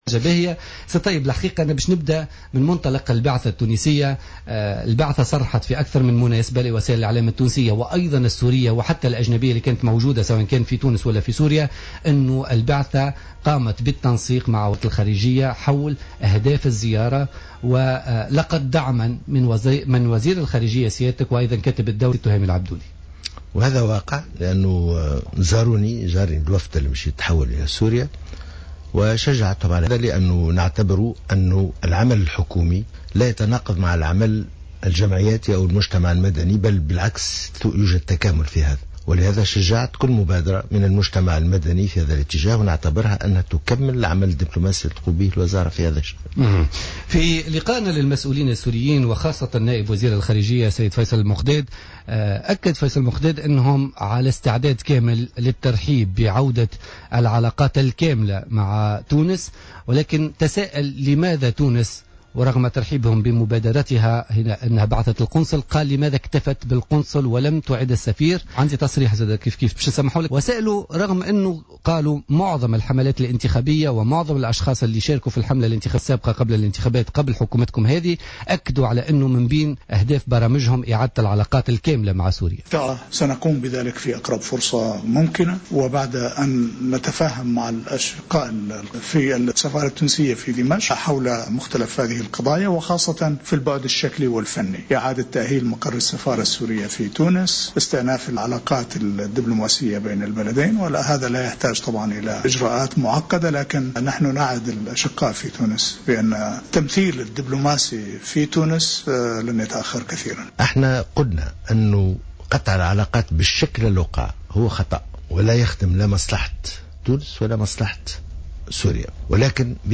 قال الطيب البكوش وزير الشؤون الخارجية اليوم الاثنين في حوار حصري على "الجوهرة أف أم" إن إعادة العلاقات سيتم بشكل تدريجي وبحسب ما تقتضيه التحولات السياسية والاقليمية.